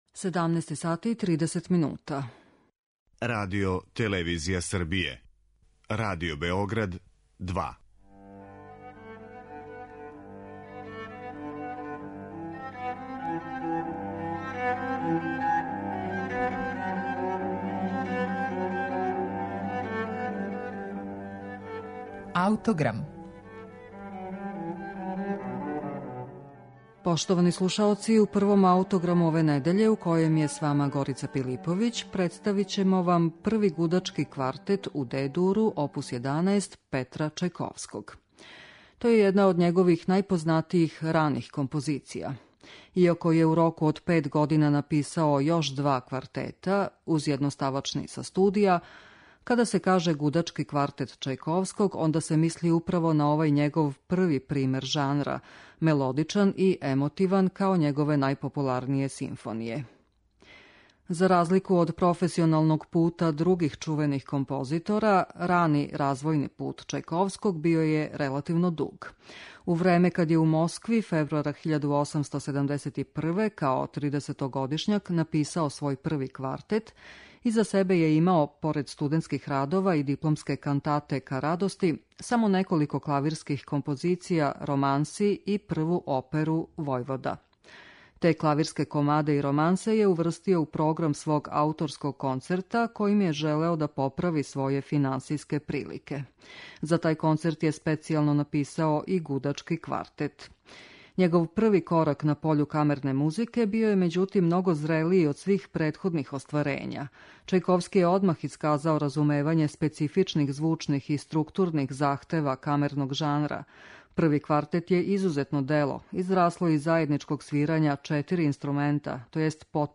Први Аутограм ове недеље испуниће први од укупно три гудачка квартета Петра Чајковског. То је уједно и прво значајно дело руске камерне музике.